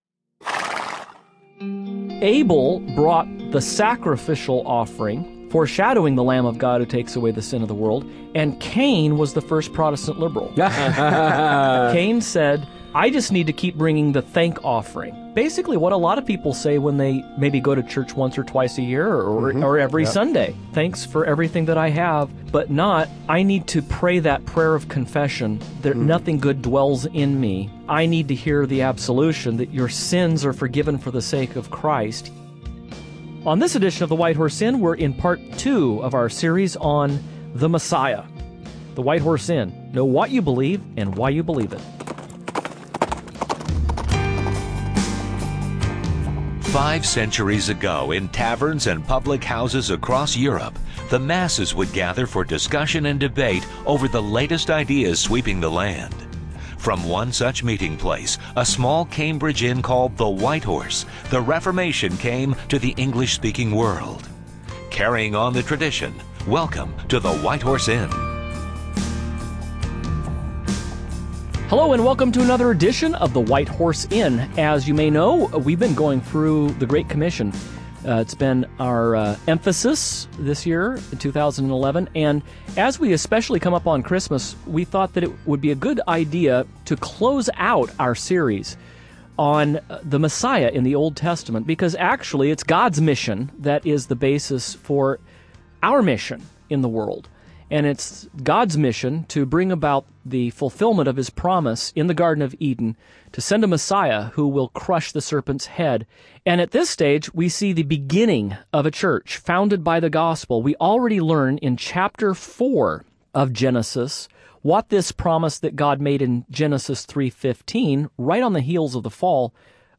Some may be able to write the gospel on a dime, but God progressively revealed over a thousand years in an unfolding drama throughout the pages of 66 books. On this edition of the program the hosts are continuing to explore Christ's messianic mission as it is announced throughout the…